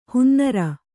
♪ hunnara